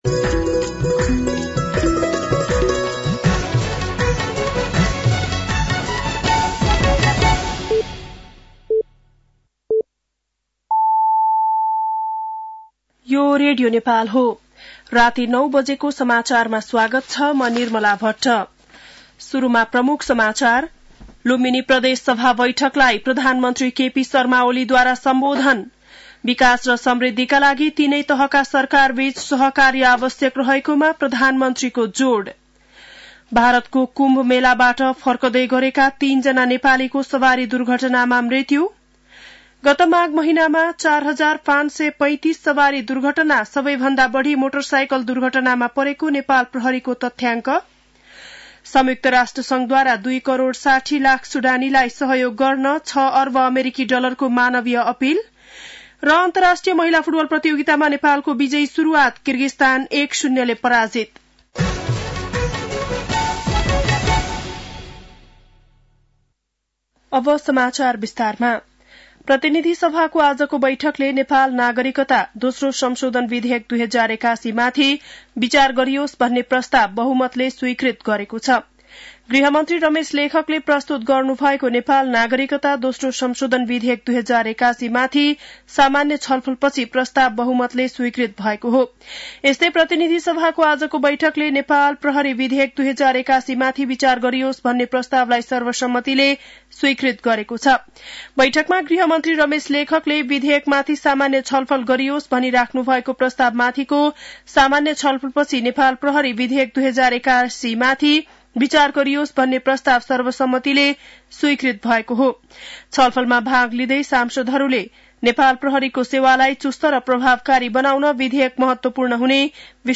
बेलुकी ९ बजेको नेपाली समाचार : ६ फागुन , २०८१
9-PM-Nepali-NEWS-11-05.mp3